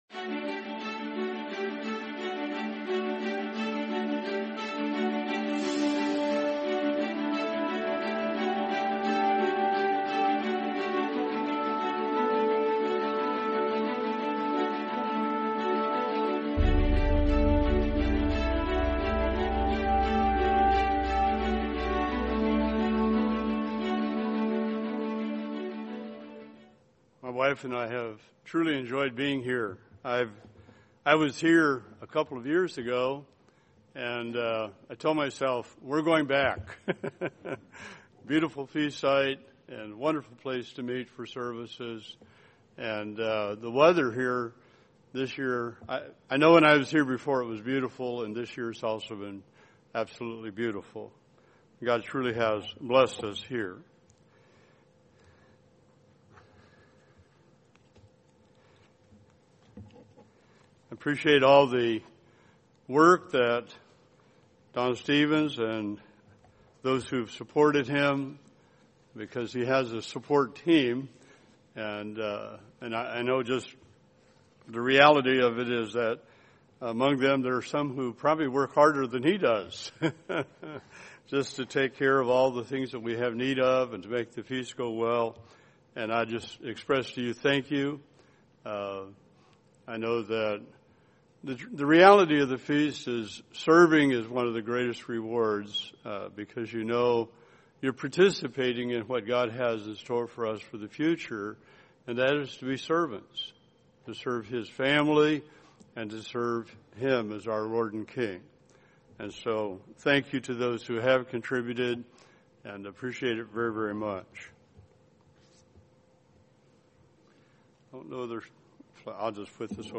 Meaning of the Last Great Day: The Goodness of God | Sermon | LCG Members